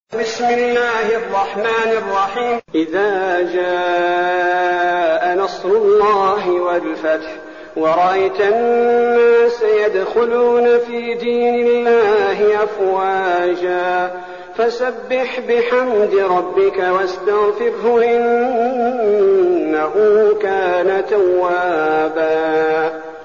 المكان: المسجد النبوي الشيخ: فضيلة الشيخ عبدالباري الثبيتي فضيلة الشيخ عبدالباري الثبيتي النصر